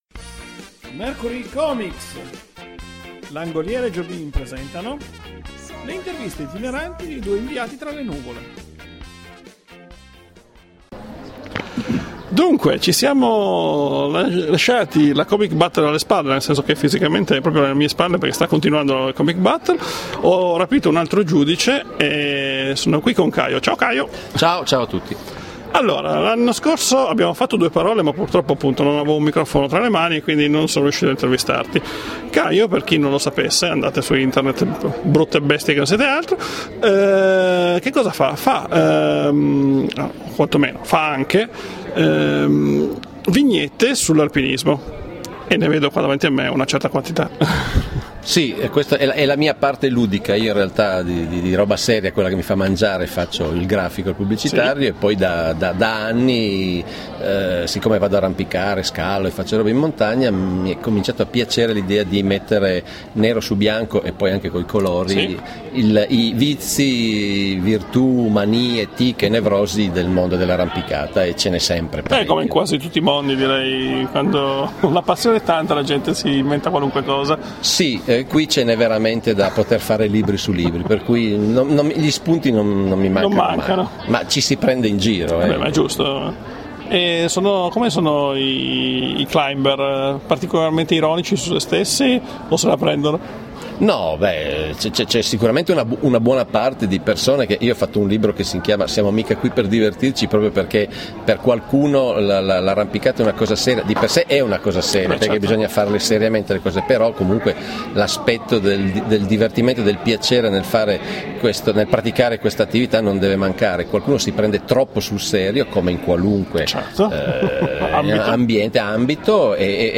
…beh, poi un MARE di interviste!!!
Intervista